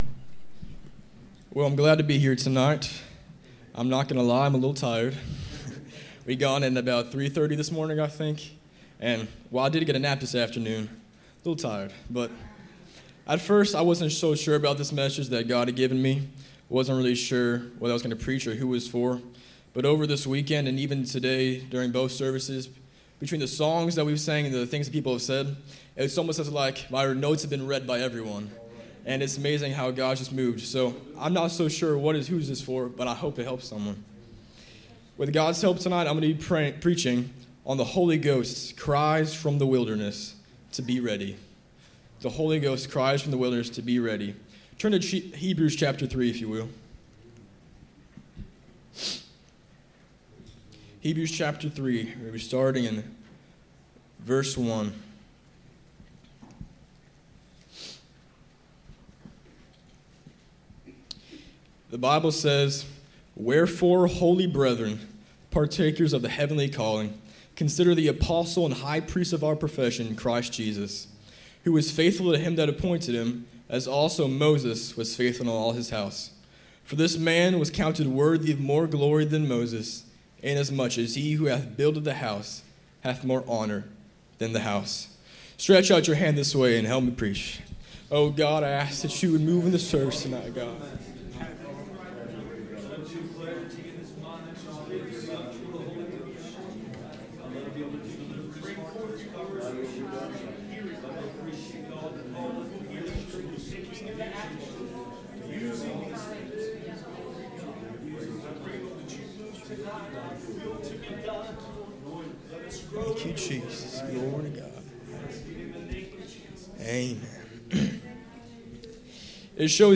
Passage: Hebrews 3:1-6 Service Type: Sunday Evening